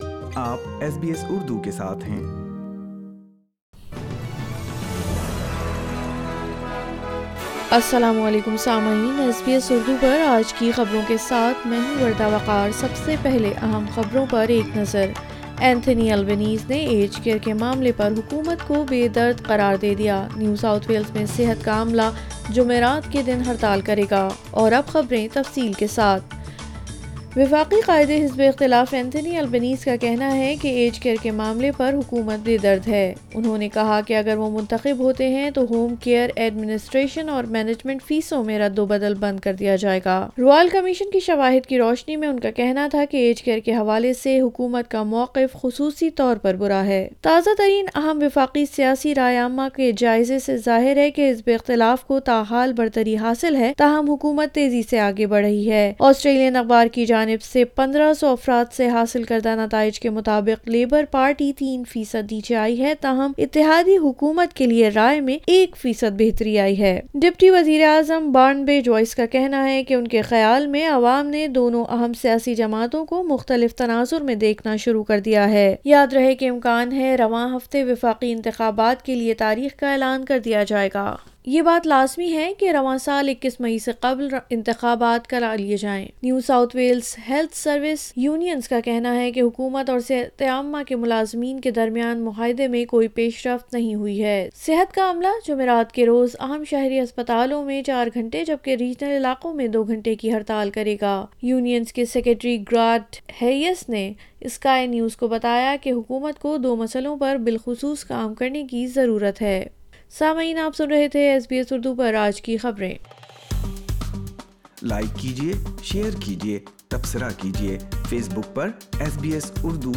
SBS Urdu News 04 April 2022